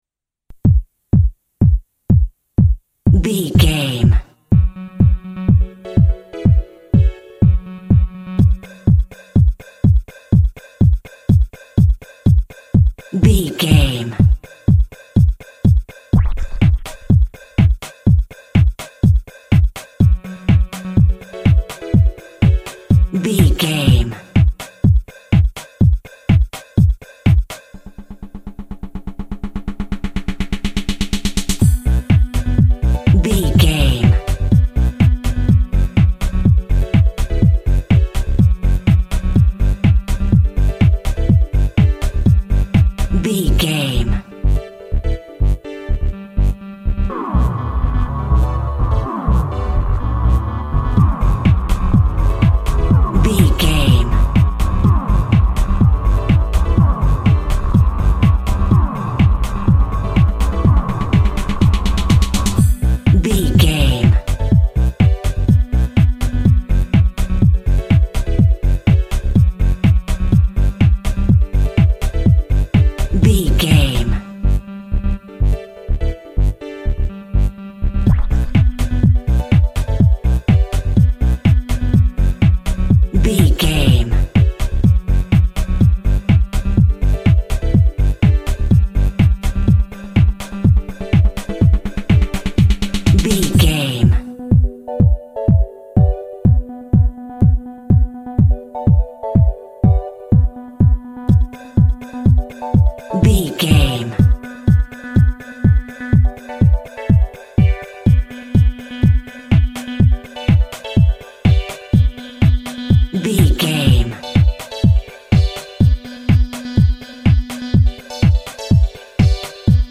Computer Game House Music.
Aeolian/Minor
funky
groovy
uplifting
driving
energetic
synthesiser
drum machine
electronic
synth lead
synth bass
electronic drums
Synth Pads